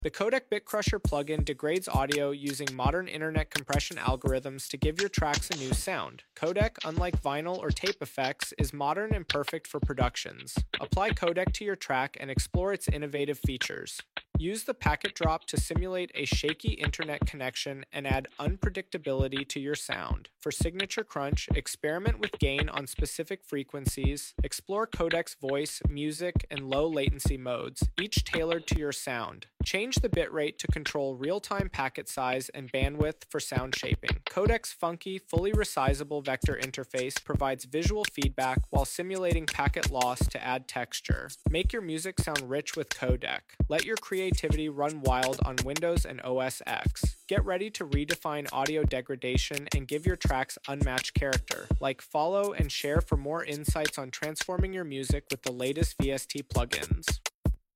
The Codec Bitcrusher plugin degrades sound effects free download
The Codec Bitcrusher plugin degrades audio using modern internet compression algorithms to give your tracks a new sound.
Use the ‘Packet Drop’ to simulate a shaky internet connection and add unpredictability to your sound.